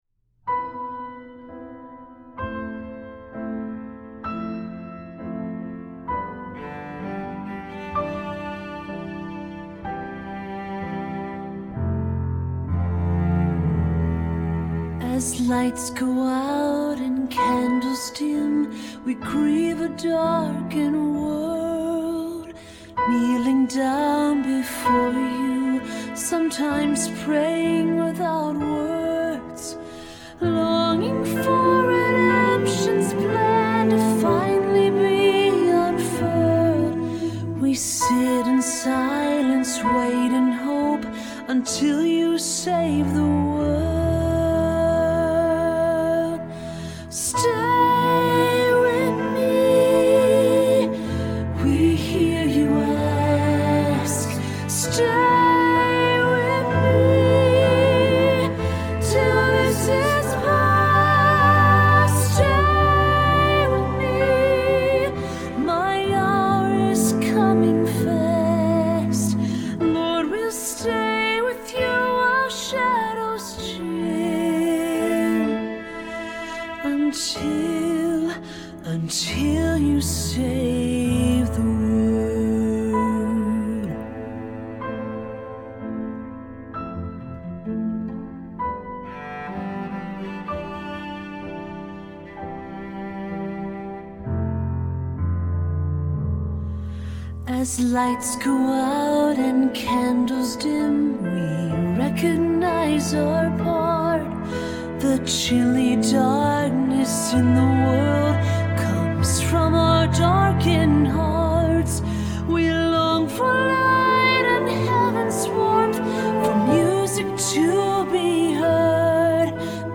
Choral Church
SATB